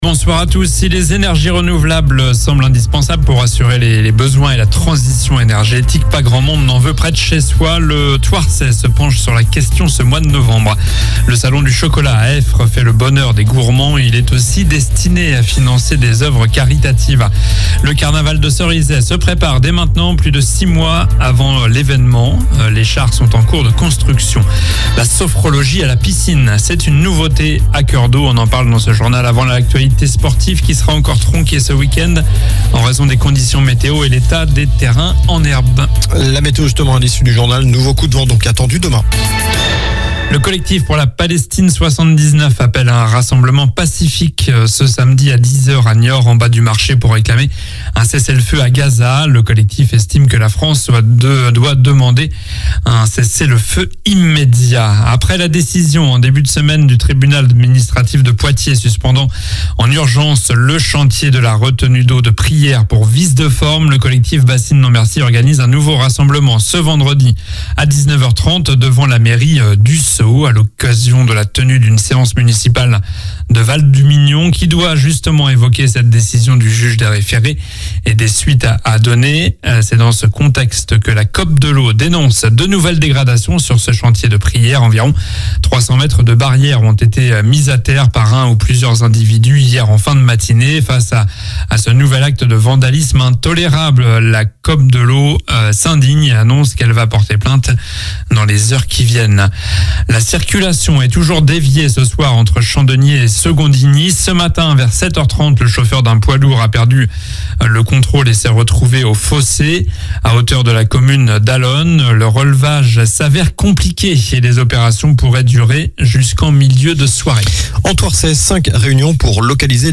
Journal du vendredi 03 novembre (soir)